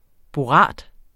Udtale [ boˈʁɑˀd ]